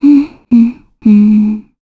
Valance-Vox_Hum_kr.wav